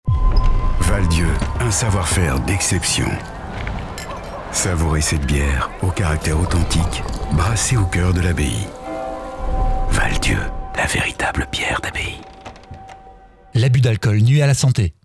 Campagne radio diffusée sur BEL RTL.